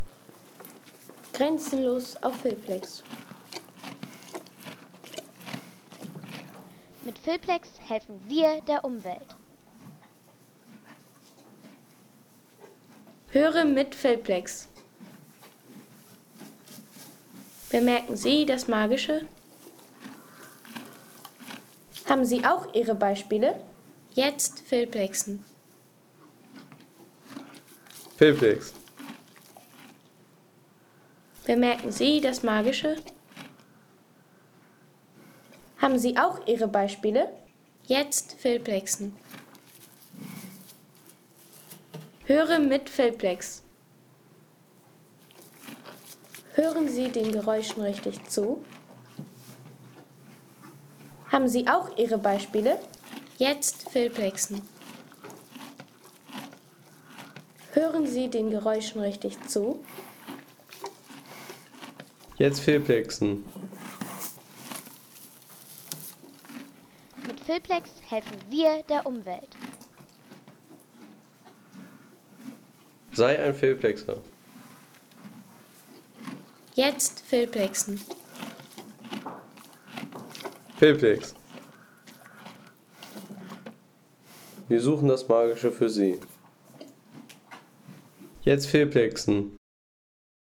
Pferdefütterung